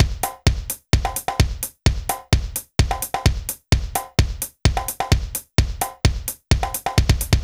BAL Beat - Mix 6.wav